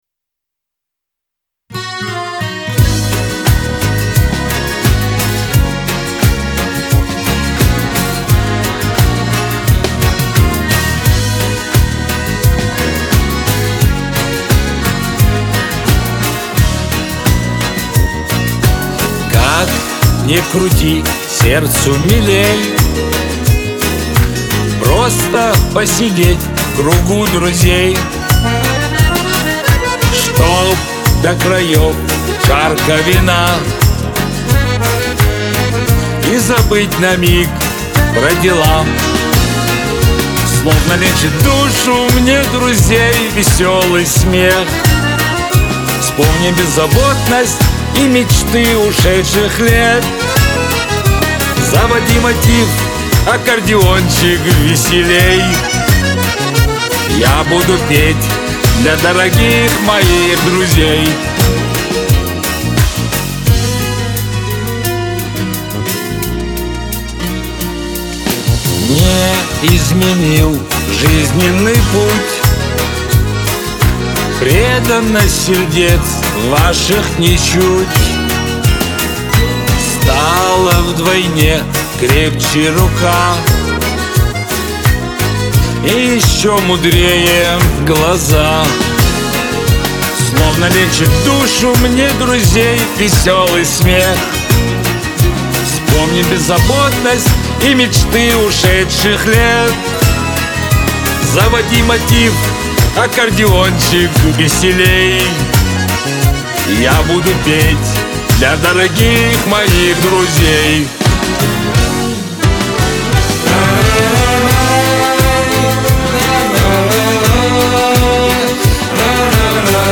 Лирика
Кавказ – поп